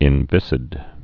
(ĭn-vĭsĭd)